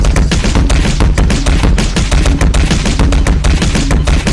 GMod ragdoll impact sounds
gmod-ragdoll-impact-sounds.mp3